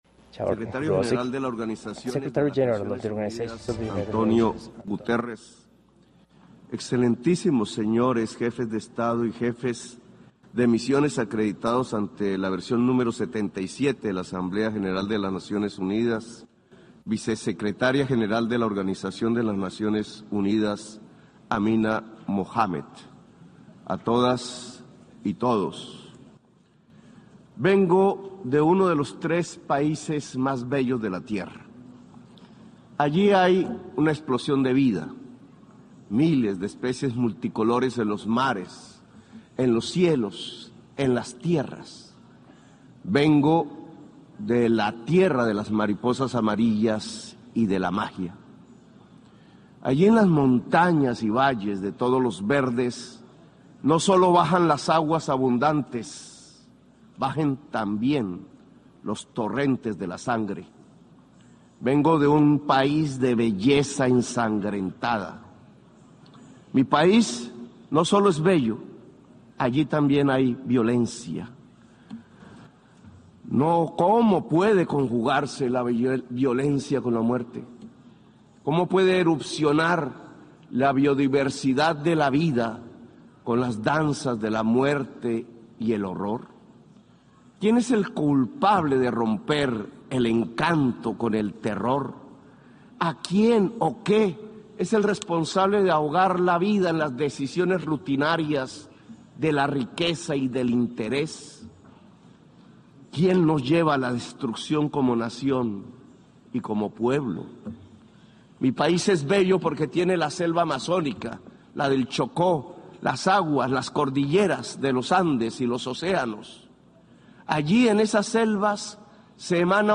Presidente Gustavo Petro ante la 77° Asamblea General de la ONU – 20 de septiembre de 2022
En su discurso en la Asamblea General, el mandatario empezó explicando que la selva amazónica está siendo destruida, enfatizando que otros gobiernos y organizaciones están detrás de ello.